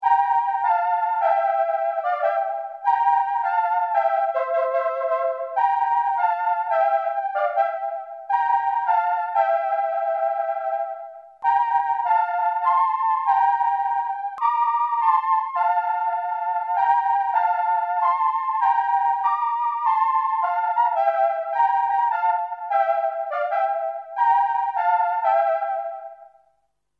The sound is very characteric to the 70s electronic period.
pe2000_chorus1.mp3